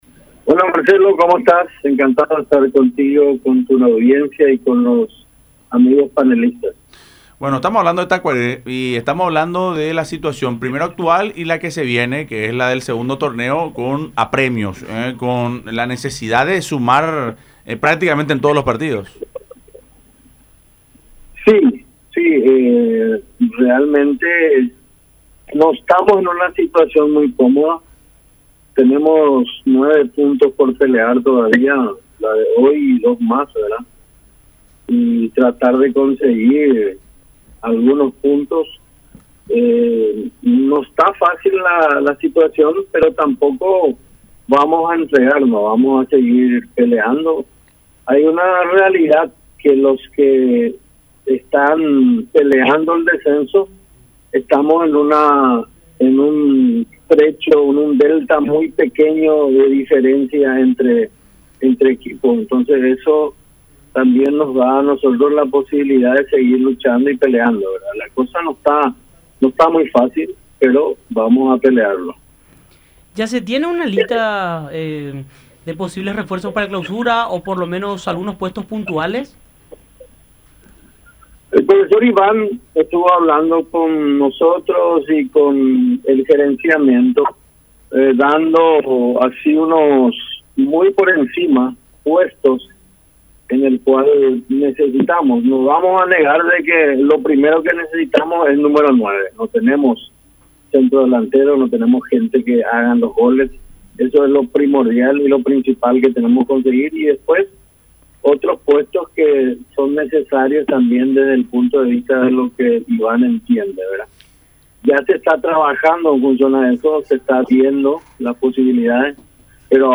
relató en charla con Fútbol Club, a través de radio La Unión y Unión TV.